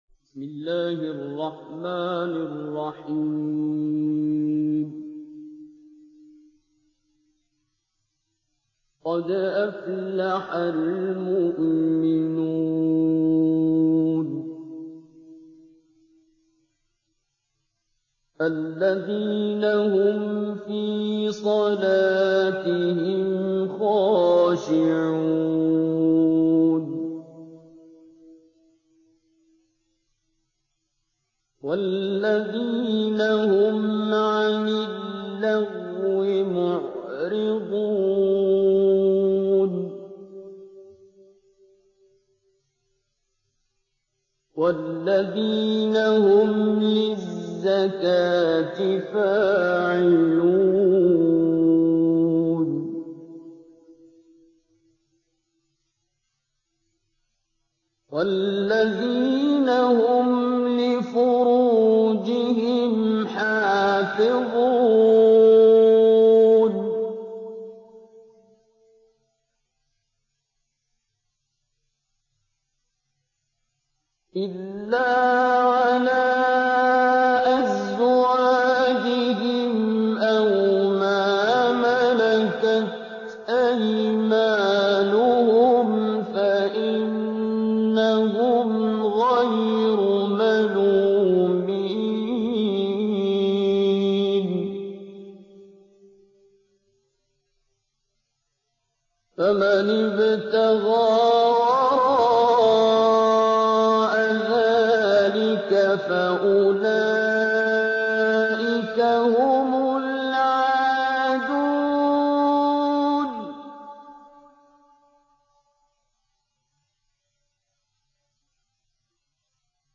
Listen to Abdul Basit Abdus Samad, renowned Quran reciter, read from a chapter of the Al-Mu’minun.
RecitationShort.mp3